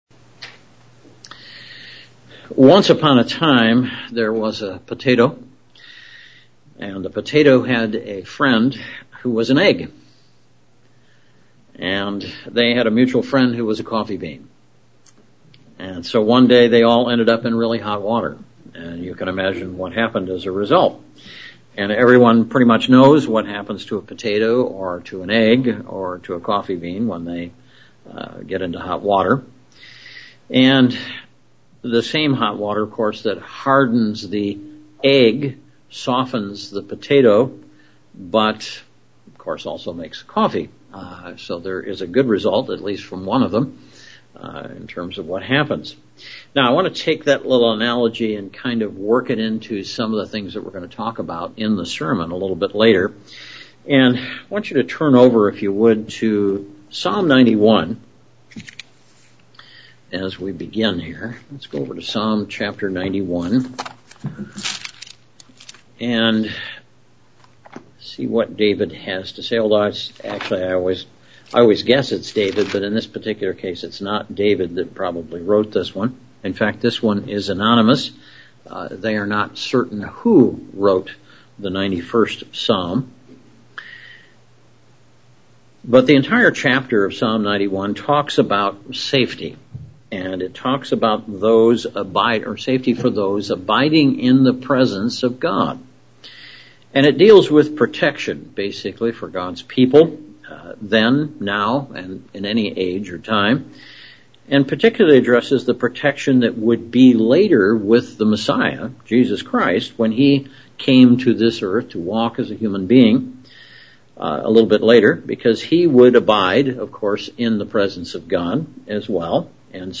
Given in Central Illinois
UCG Sermon Studying the bible?